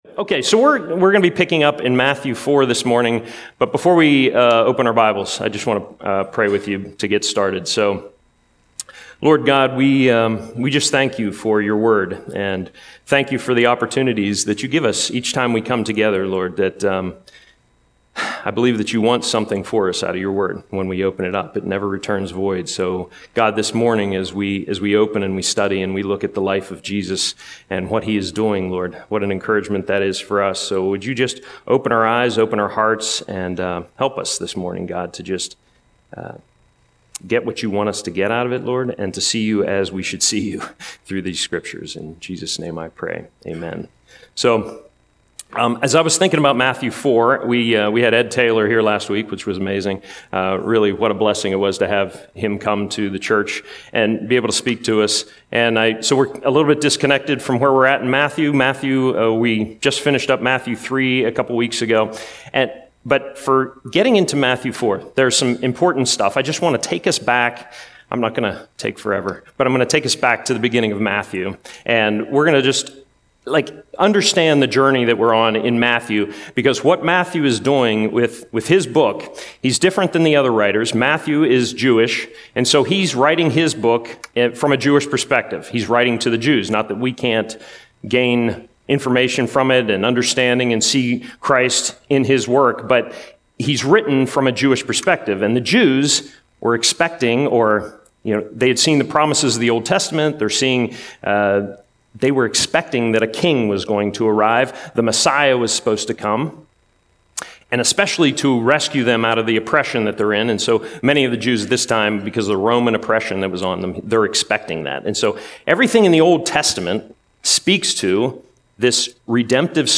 Sermon - October 26, 2025